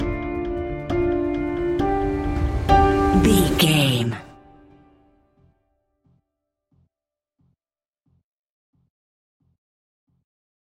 Ionian/Major
D
techno
synths
synthwave